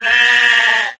dubwool_ambient.ogg